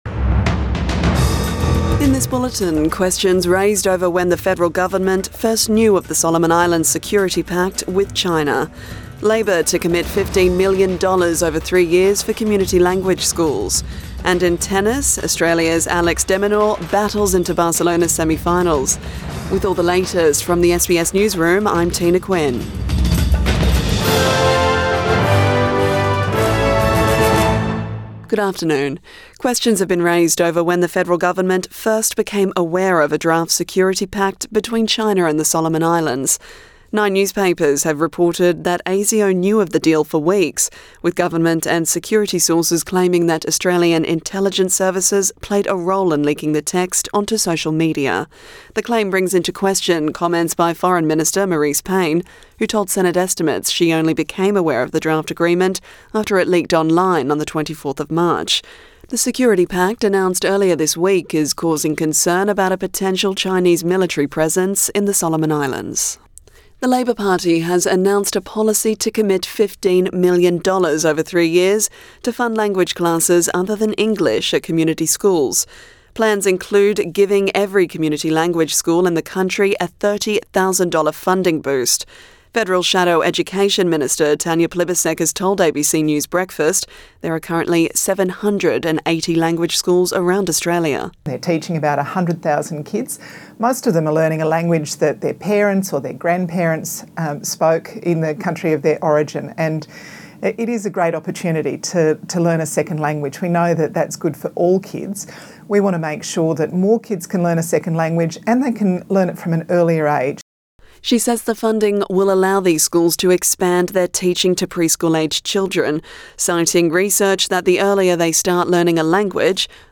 Midday bulletin 23 April 2022